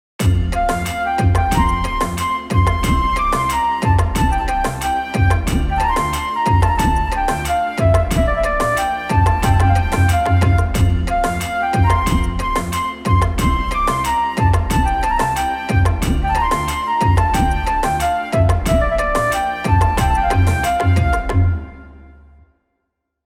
Instrumental
• Category: Devotional / Hanuman Bhajan